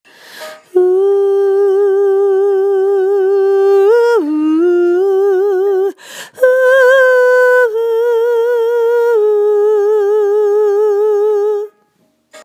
download OEH-tjes